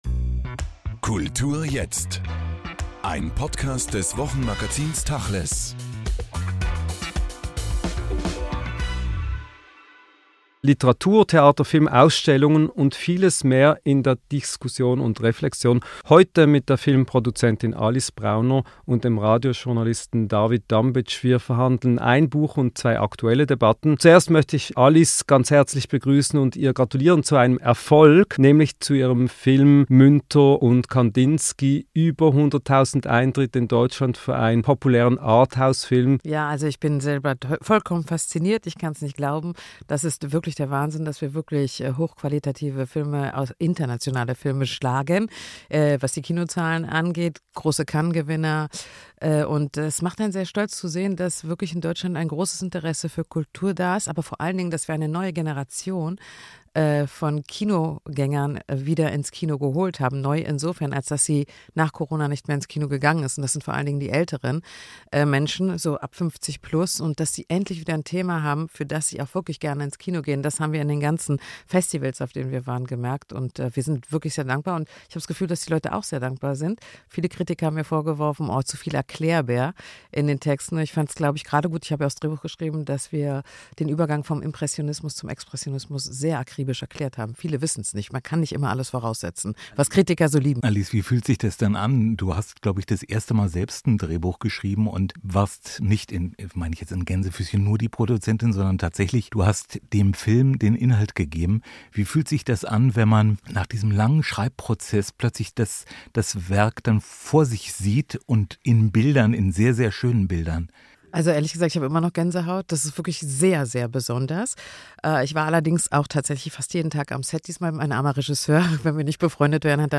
Der neue Podcast «Kultur jetzt» verhandelt Literatur, Theater, Film, Ausstellungen und vieles mehr in Diskussion und Reflexion.